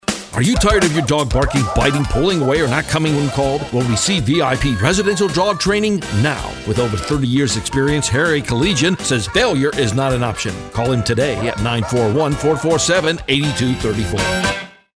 WENG Radio Advertisement